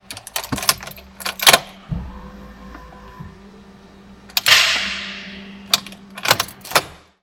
Schlüssel rein und bis auf Zündung, "Klack", und Schlüssel wieder abgezogen.
Das ist ganz eindeutig der Anlasser / Magnetschalter (vorausgesetzt Batterie auch voll genug, sonst wird aber auch im Startmoment alles dunkel am Kombi)
E38_Anlasser_Klack.mp3